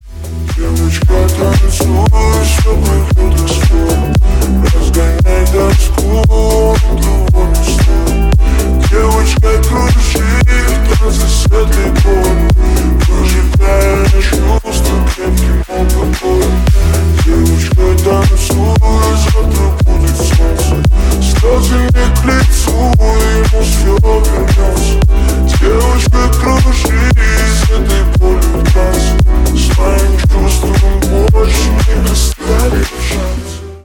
Ремикс
клубные # грустные